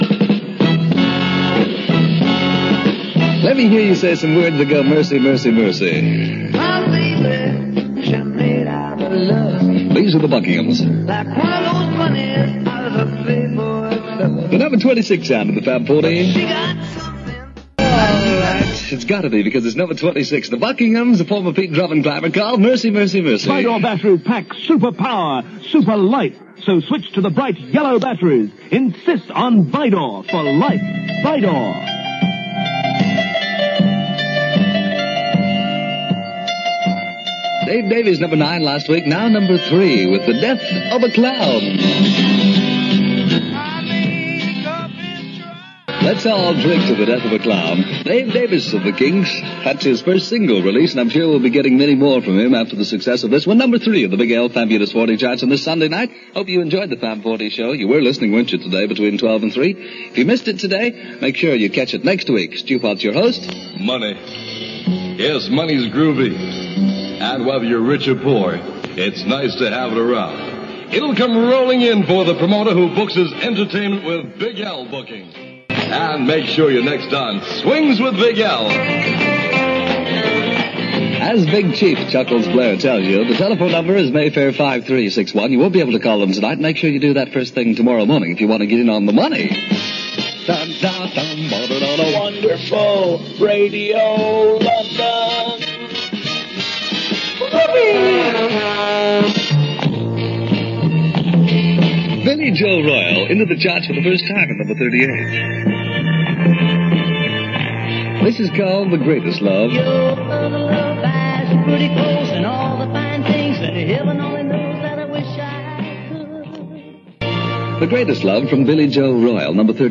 talking to the “guys and gals, little ones and kittens” on Radio London from Sunday evening 23rd July 1967.